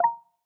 sndMessage.wav